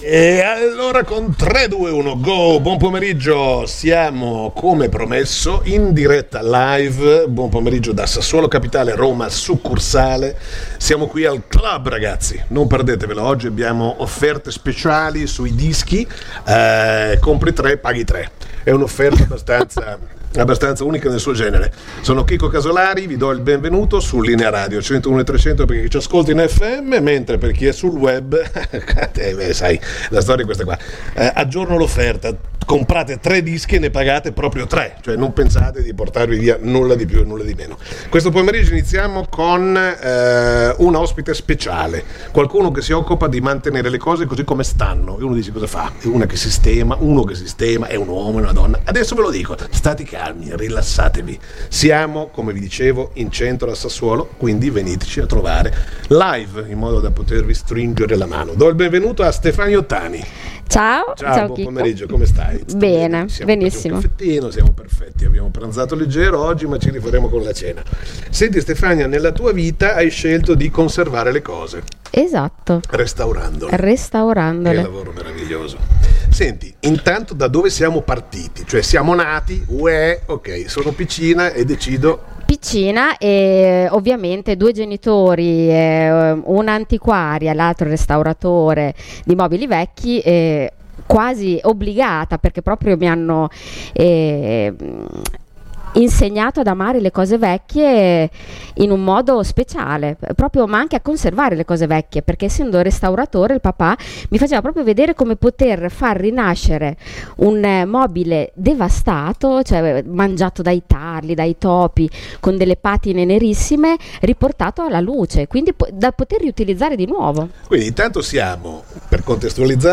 Le interviste di Linea Radio al Clhub di viale XX Settembre a Sassuolo